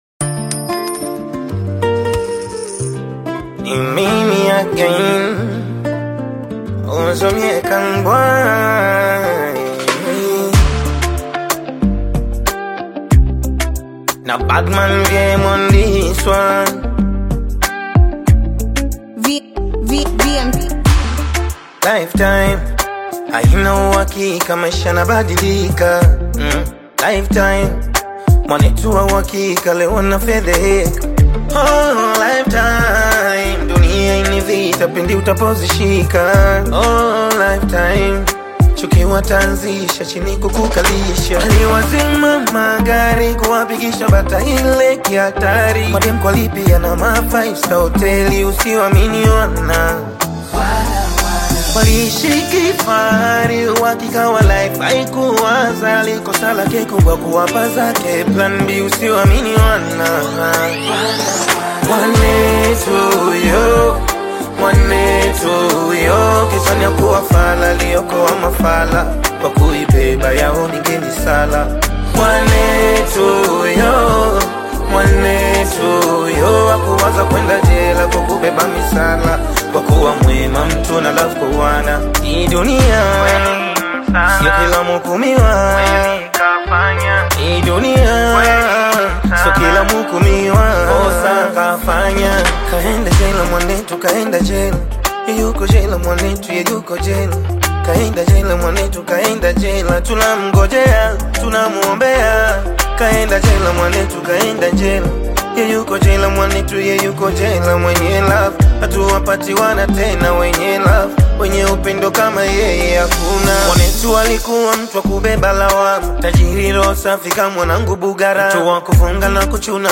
is a heartfelt Bongo Flava single released on January 21